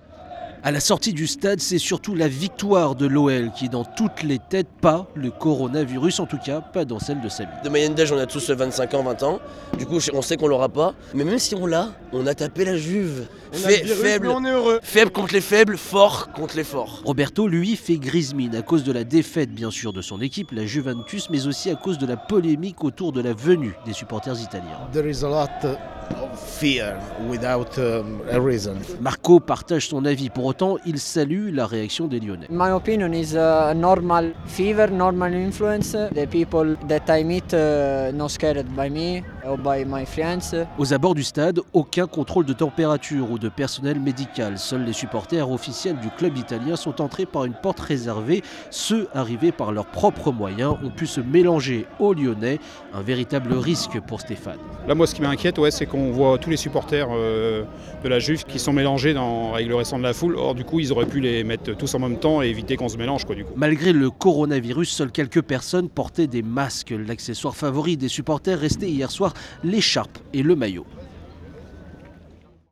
Des supporters italiens, interrogés à la fin de la rencontre